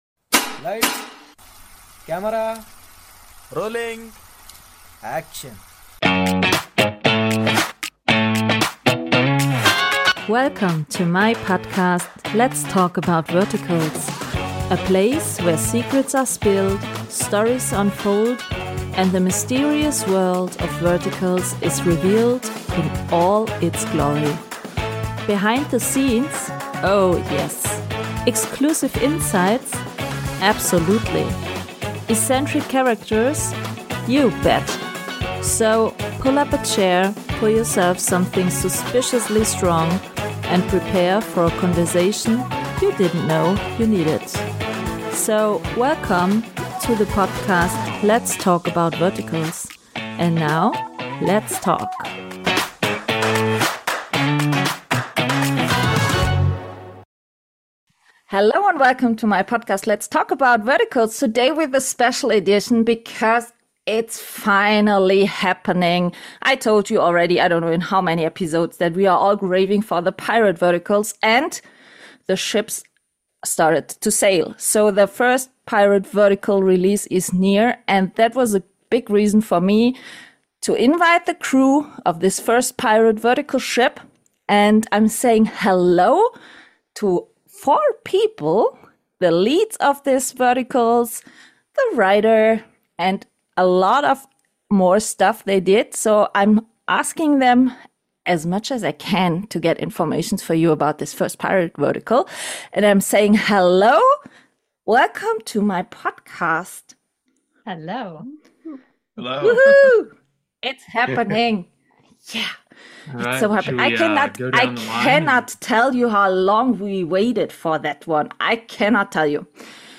I had the chance to speak exclusively in advance with four of the key people behind the project.